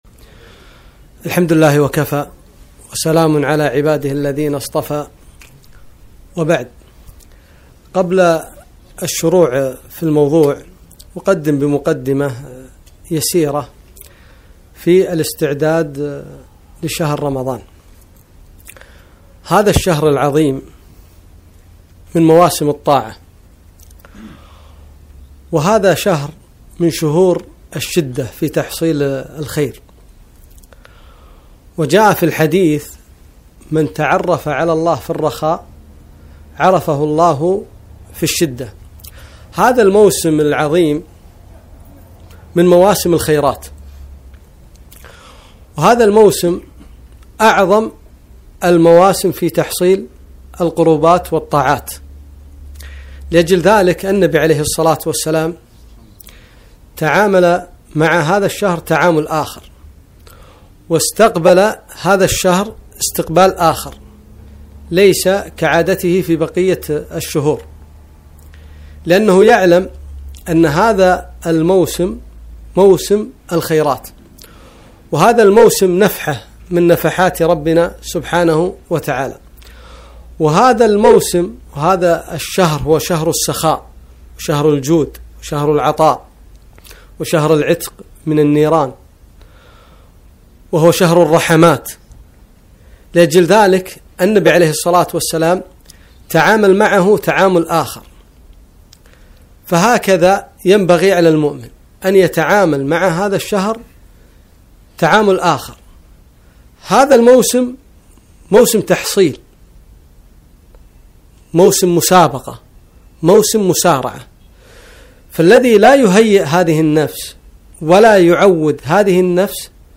كلمة - أقبل رمضان